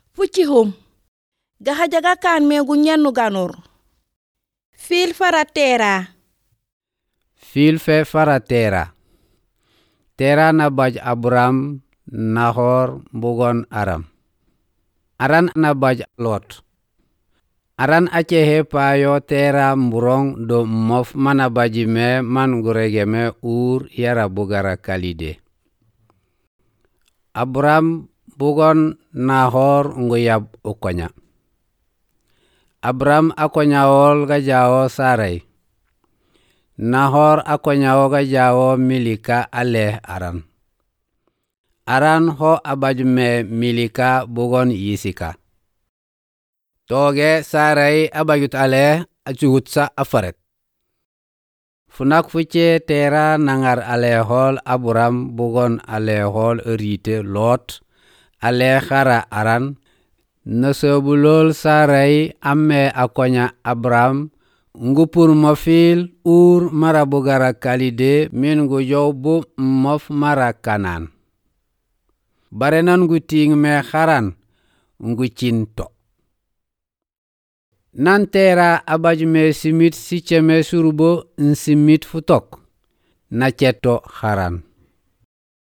Ecouter une version dramatique de l'histoire de la vie d'Abraham avec des chansons.
Effets sonores utilisés avec permission.